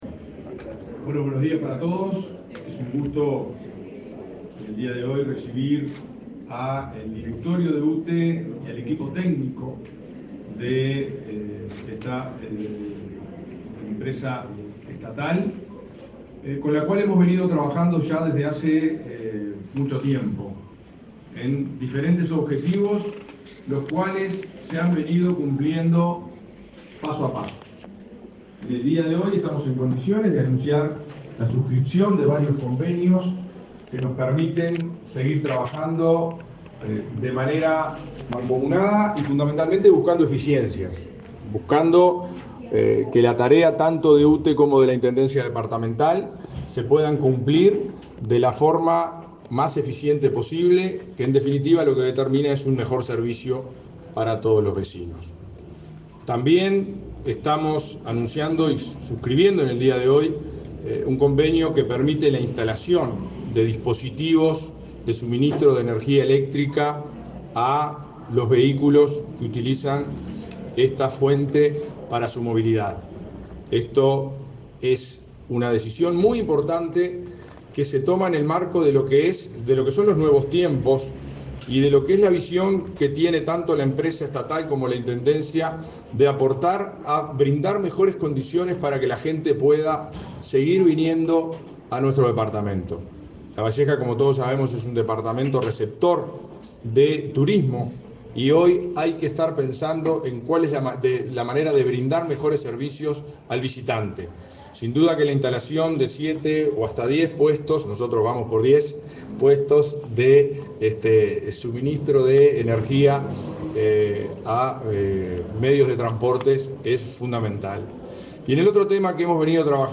Palabras del intendente de Lavalleja y de la presidenta de UTE